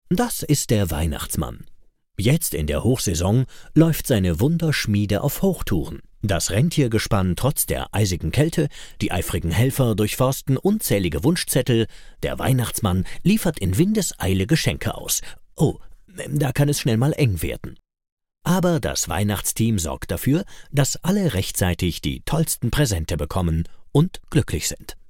Male
Assured, Authoritative, Confident, Corporate, Engaging, Friendly, Natural, Reassuring, Warm
E-Learning_Medical.mp3
Microphone: Brauner VM 1, AKG414 B